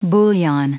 Prononciation du mot bouillon en anglais (fichier audio)
Prononciation du mot : bouillon